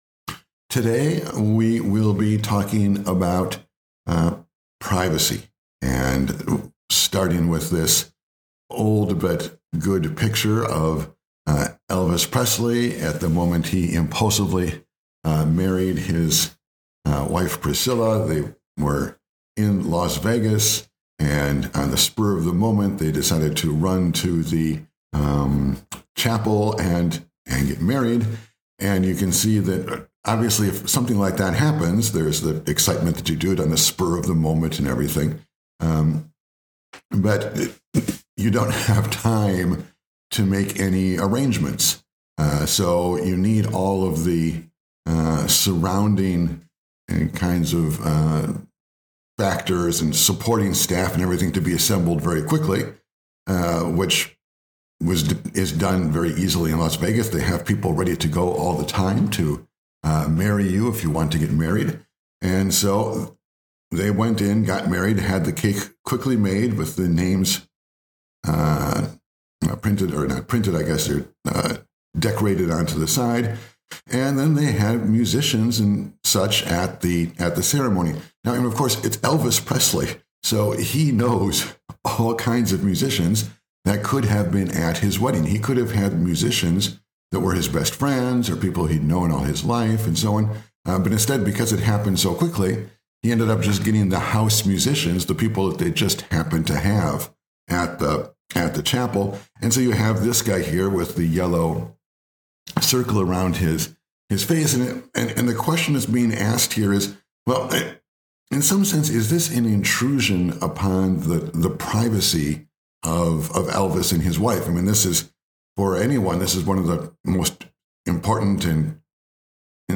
Privacy Lecture (1/2) Privacy Lecture 2/3 Short on time?
The lecture is meant to include images, but there is a pure audio version here that you may download.